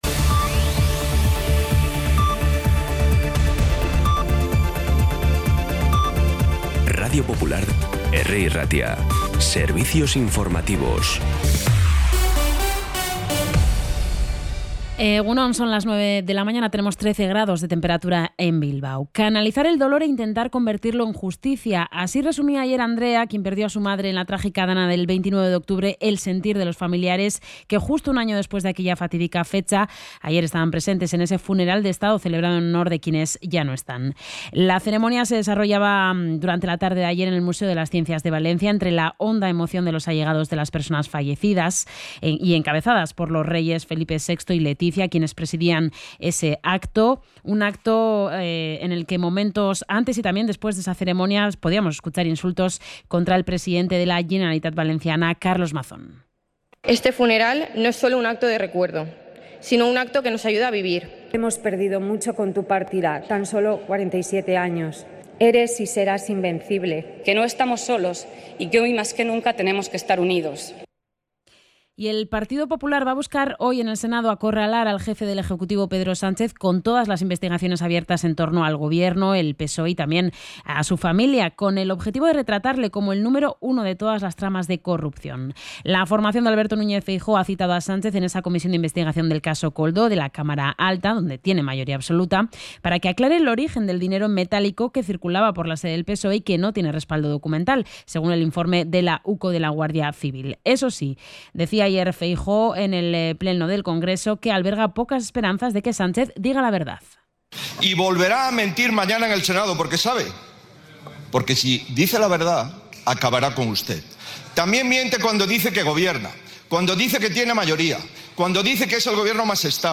Las noticias de Bilbao y Bizkaia de las 9 , hoy 30 de octubre
Los titulares actualizados con las voces del día. Bilbao, Bizkaia, comarcas, política, sociedad, cultura, sucesos, información de servicio público.